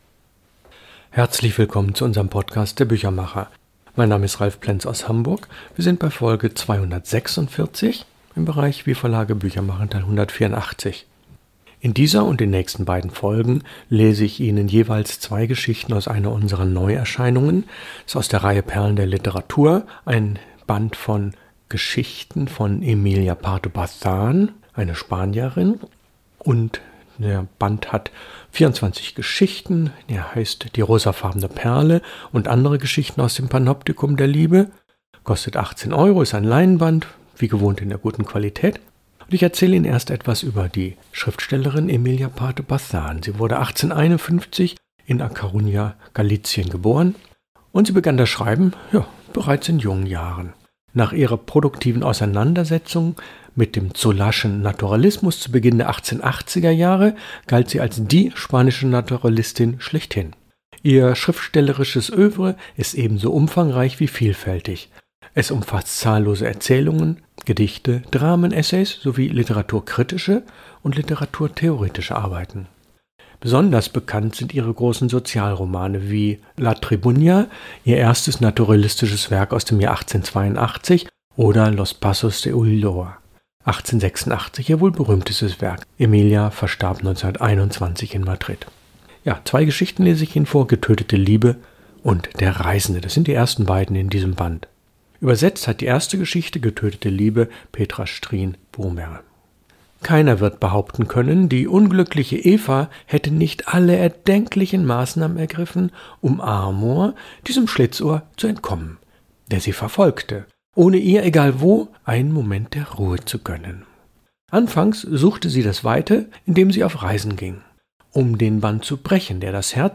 Lesung aus: Emilia Pardo Bazán – Die rosafarbene Perle ... aus dem Panoptikum der Liebe, Folge 1 von 3